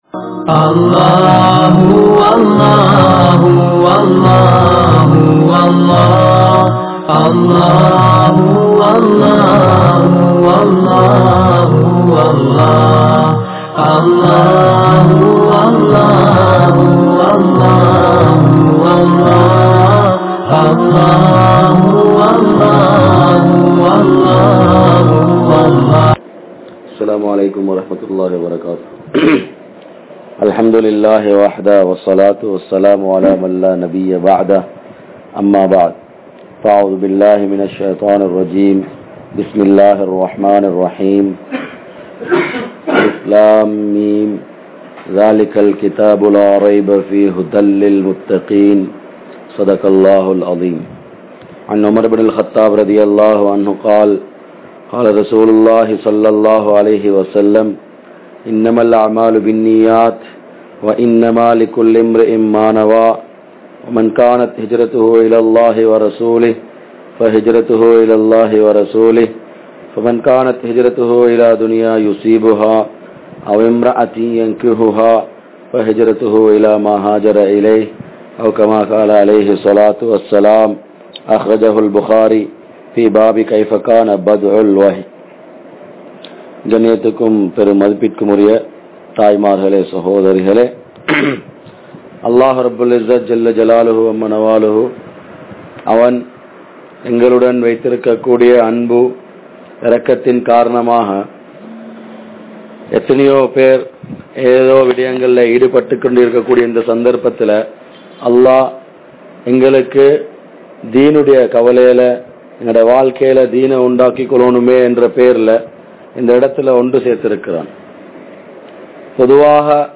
Kudumba Vaalkai (குடும்ப வாழ்க்கை) | Audio Bayans | All Ceylon Muslim Youth Community | Addalaichenai
Aluthgama, Dharga Town, Meera Masjith(Therupalli)